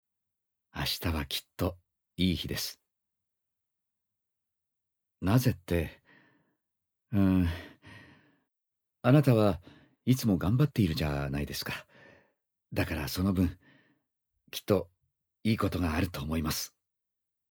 Voice同棲花盛り 井上和彦の場合 -真守・弦二-